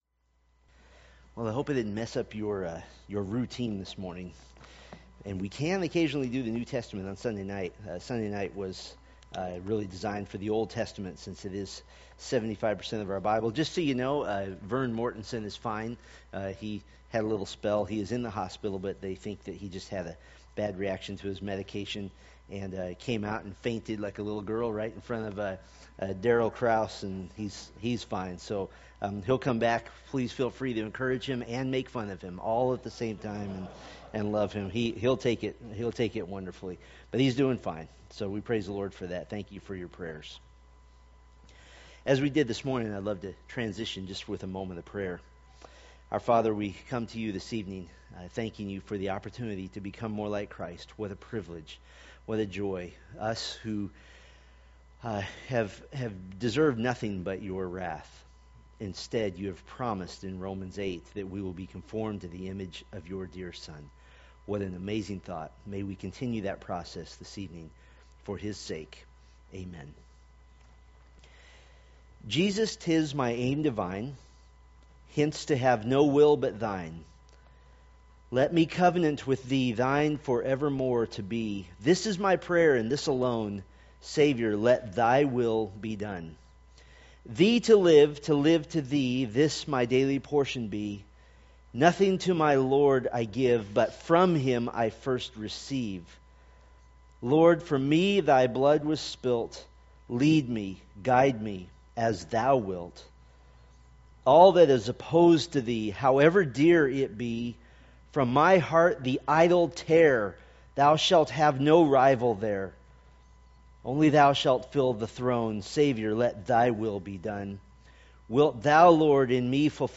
1 Thessalonians Sermon Series